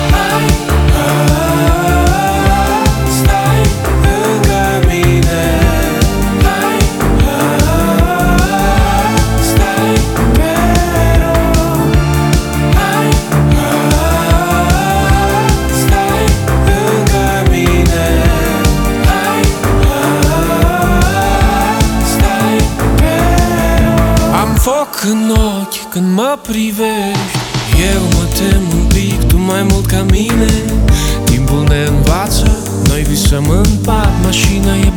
Скачать припев
2025-05-28 Жанр: Поп музыка Длительность